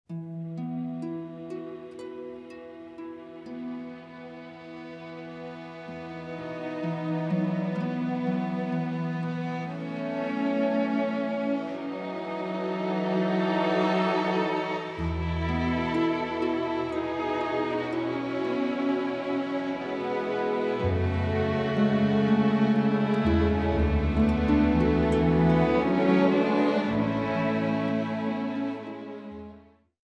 彼らの目指すクラッシクスタイルの新しい解釈・アレンジ・演奏により、新たなる魅力が吹き込まれる。
シドニーフォックススタジオEQで録音され